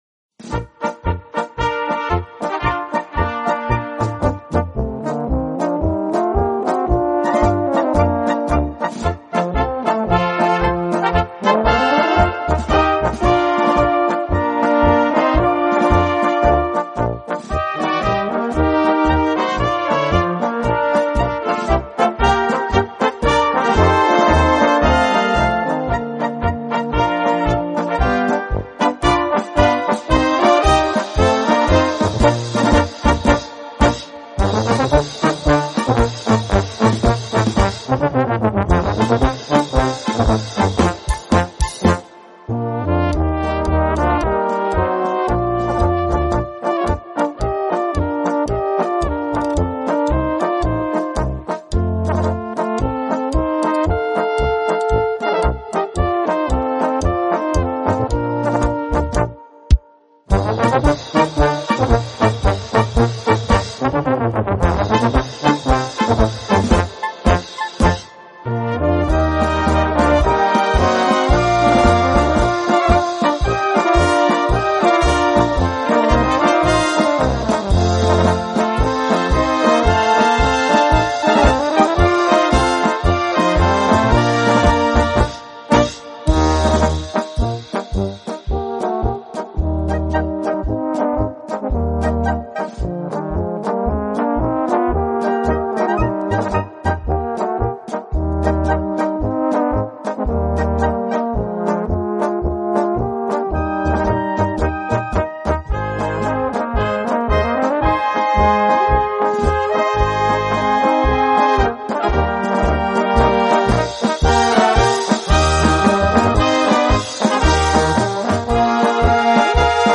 Gattung: Polka für böhmische Besetzung
Besetzung: Kleine Blasmusik-Besetzung
Traumhafte Polka